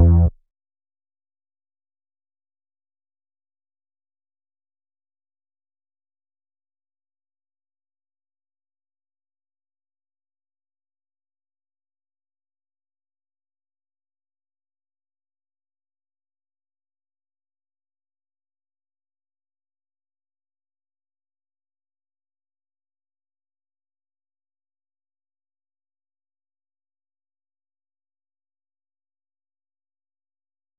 Bass